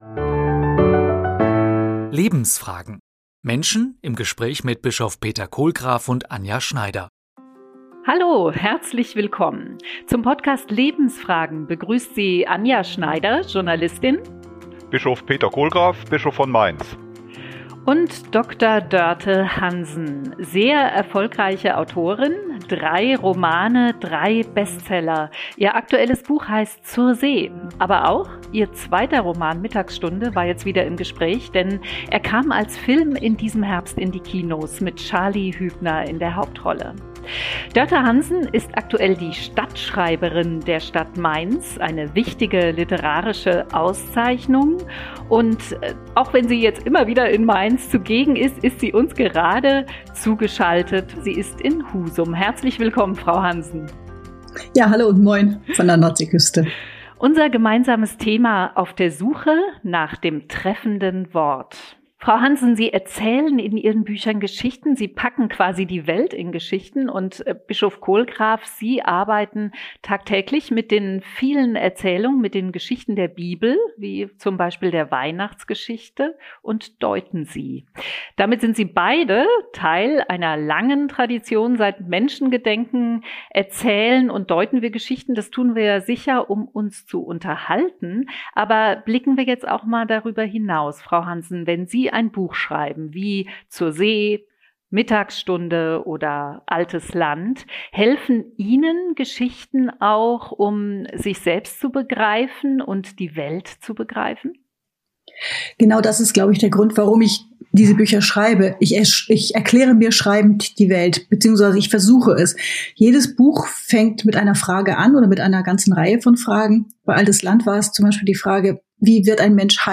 Zu Gast: Dörte Hansen.